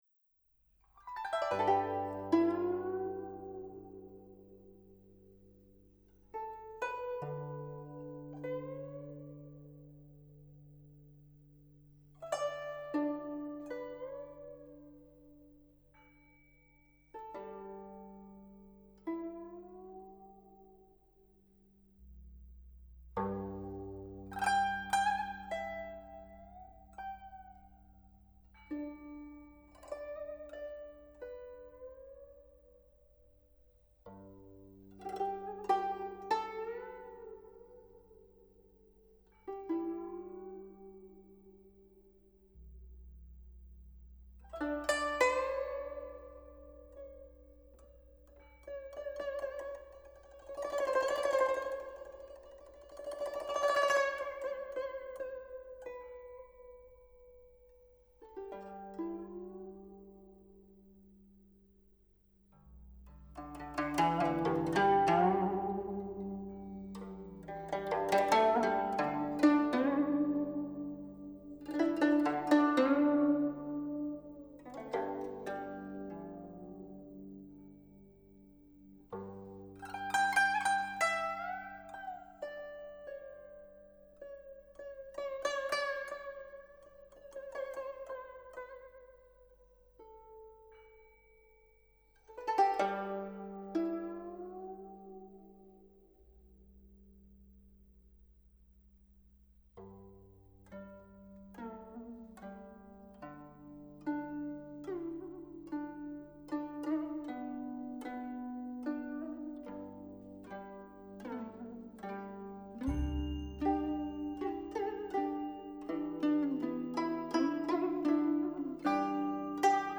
★ 技藝超群的七仙女以音符描繪大唐盛景！